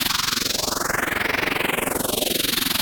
Index of /musicradar/rhythmic-inspiration-samples/85bpm
RI_RhythNoise_85-01.wav